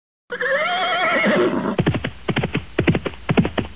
- 新增马蹄声音效
horse.mp3